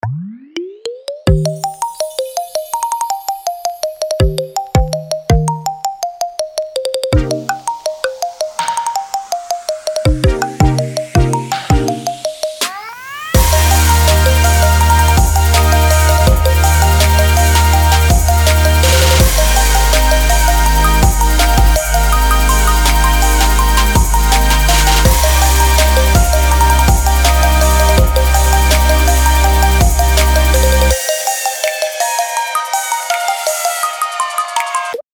• Качество: 320, Stereo
свист
веселые
без слов
легкие